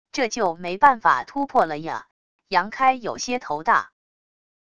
这就没办法突破了啊……杨开有些头大wav音频